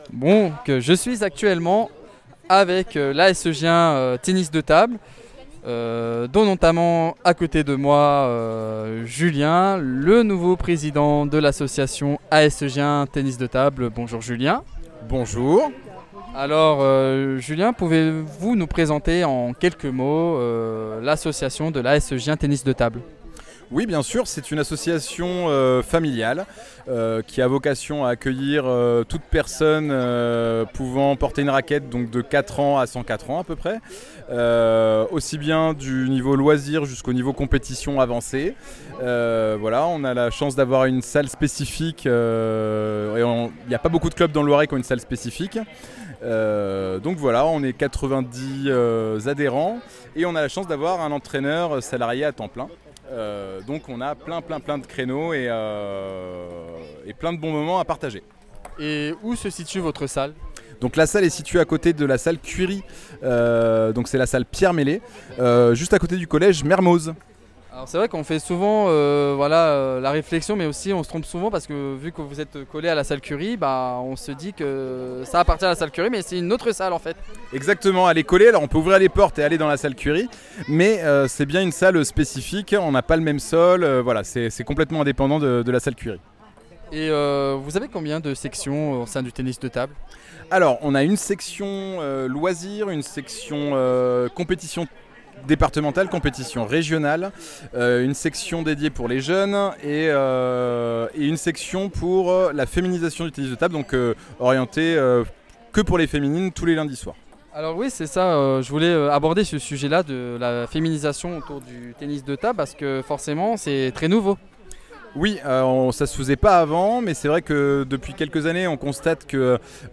Fête des associations de Gien de 2025 - AS Gien Tennis de Table